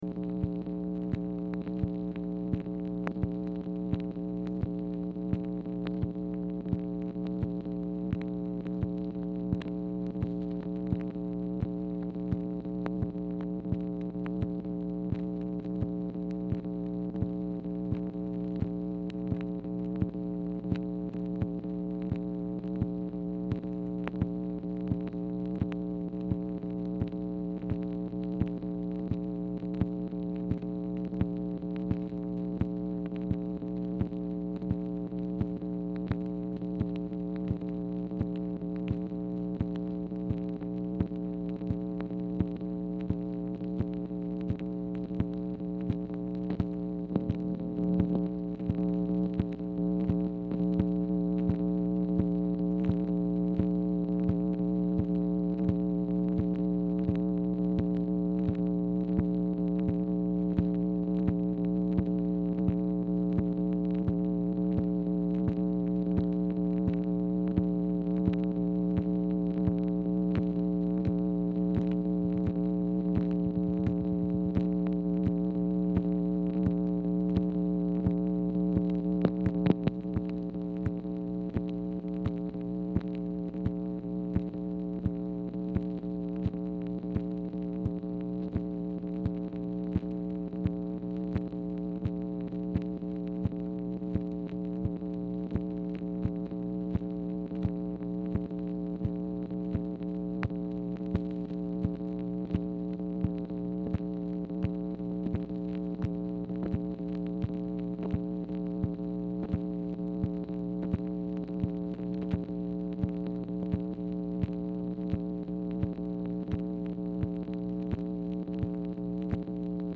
Telephone conversation # 8520, sound recording, MACHINE NOISE, 8/6/1965, time unknown | Discover LBJ
Format Dictation belt
Speaker 2 MACHINE NOISE